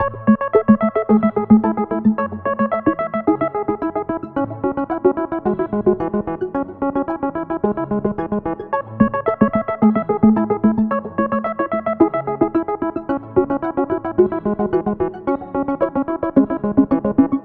肥胖的合成风琴的旋律
描述：FG管乐器Syth/电子琴； 8bars 110bpm
标签： 110 bpm Electronic Loops Organ Loops 2.94 MB wav Key : D
声道立体声